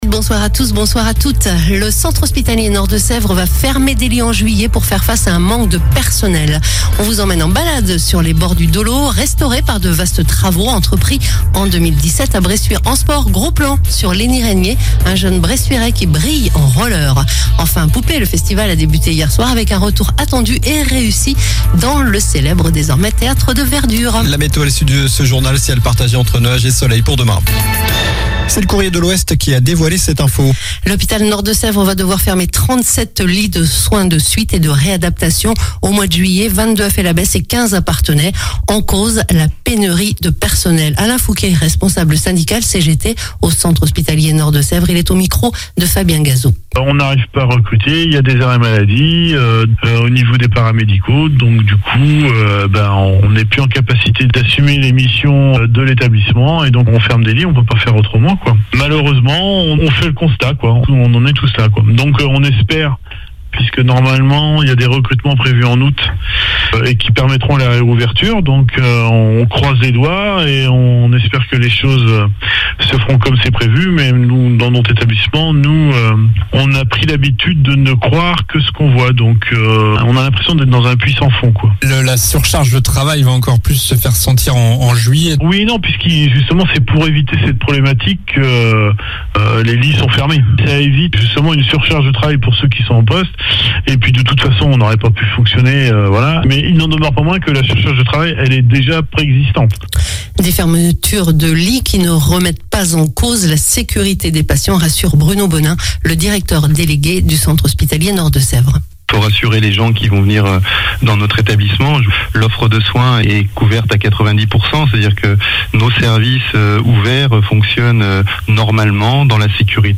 Journal du jeudi 30 juin (soir)